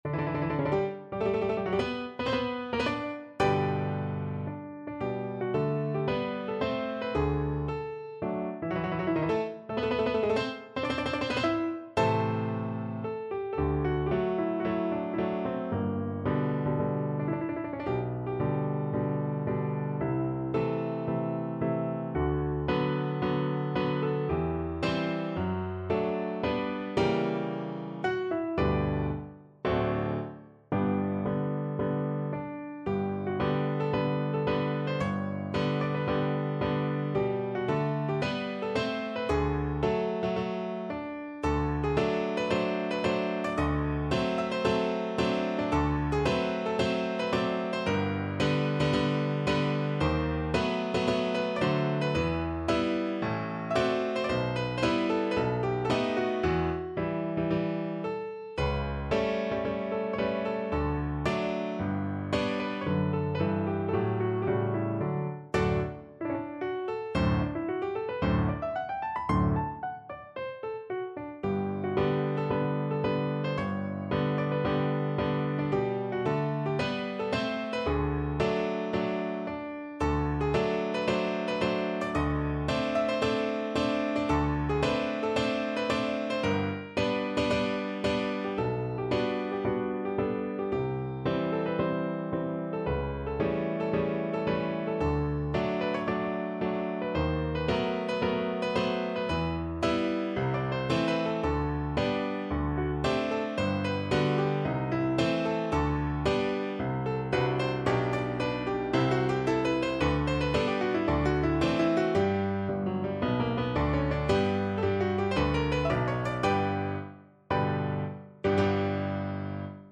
Piano version
No parts available for this pieces as it is for solo piano.
4/4 (View more 4/4 Music)
Tempo di marcia = c. 112
Piano  (View more Intermediate Piano Music)